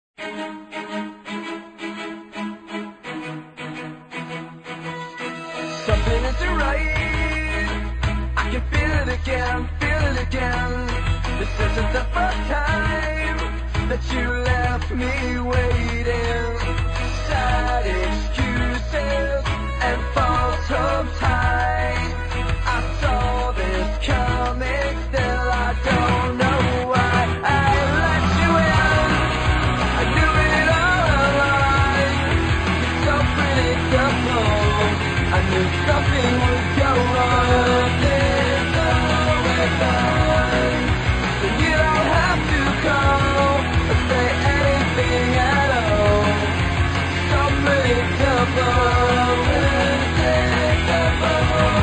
Главная страница>>Скачать mp3>>Рок рингтоны